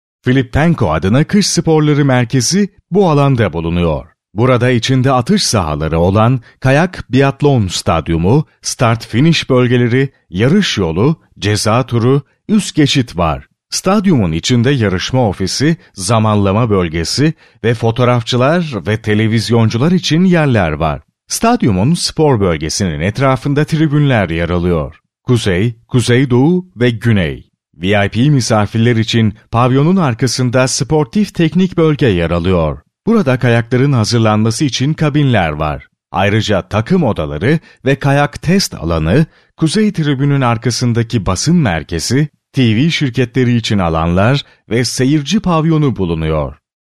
Masculino
Turkish Corporate Narration
• Warm
From medium friendly read to articulate and energetic hard-sell.